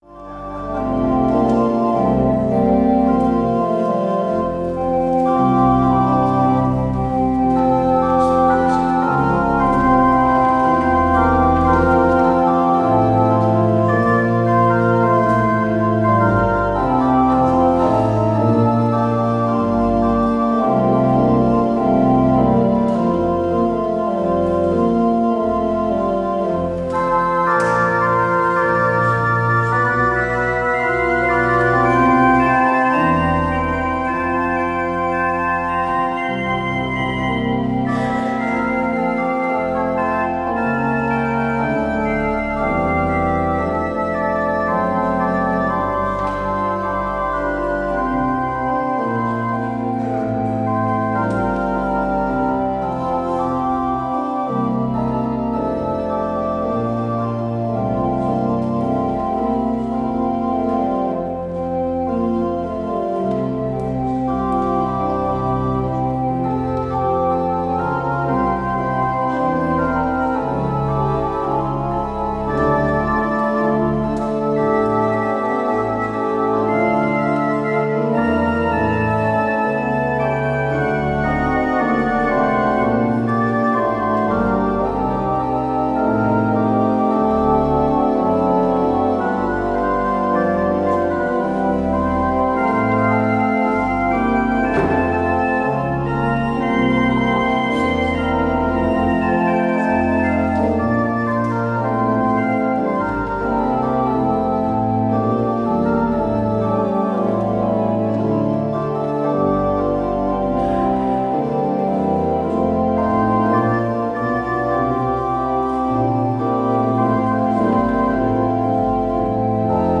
Het slotlied is: NLB 835: 1, 2, 3 en 4.